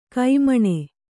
♪ kai maṇe